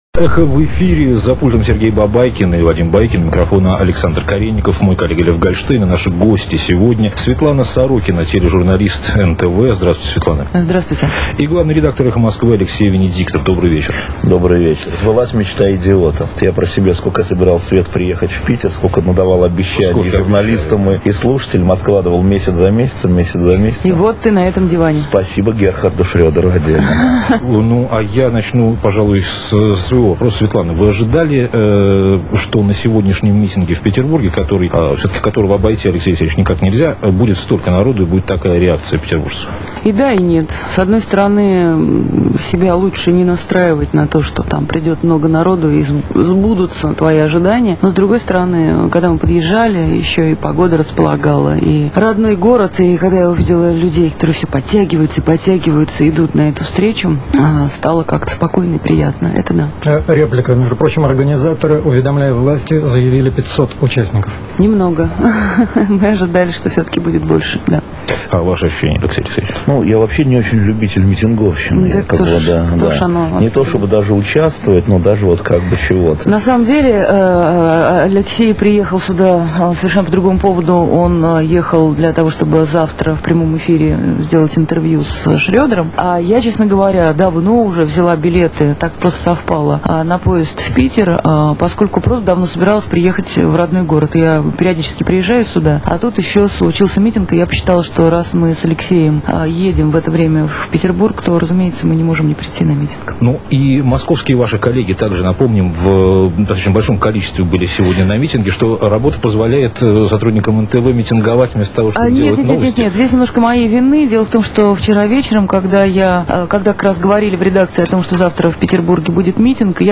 Без посредников (Эхо Москвы в Петербурге, 8.04.2001) Интервью Светланы Сорокиной и Алексея Венедиктова
Гости программы: Светлана Сорокина и Алексей Венедиктов